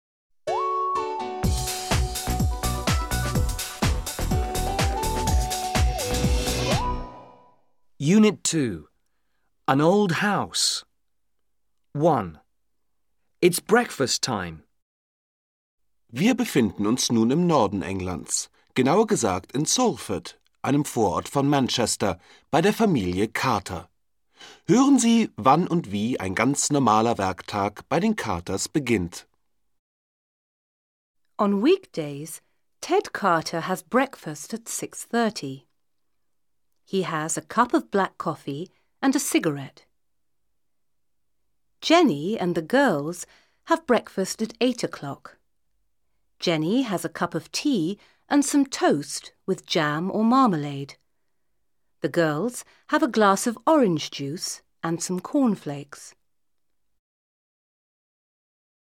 Audio ist Trumpf: Das Set enthält 6 Audio-CDs, die den Kurs mit den Lektionstexten, alltagsnahen Hörspielen und Übungen begleiten